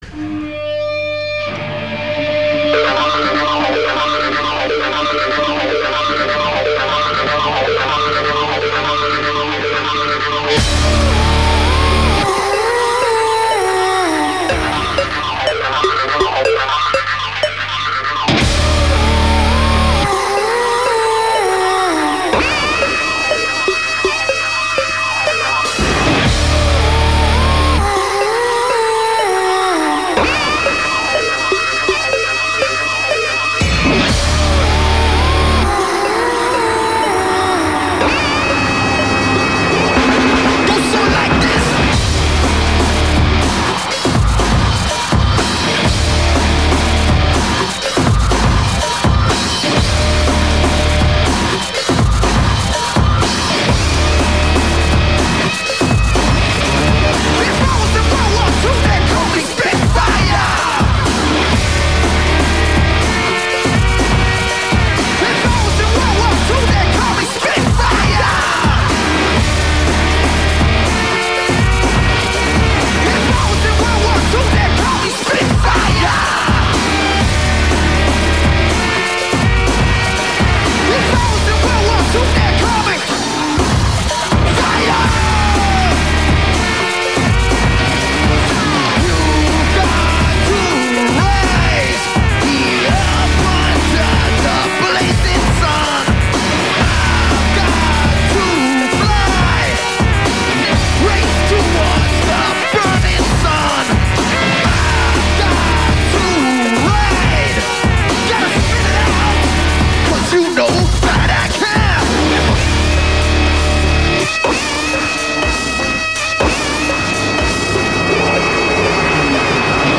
Completely reworked the classics into something crazy!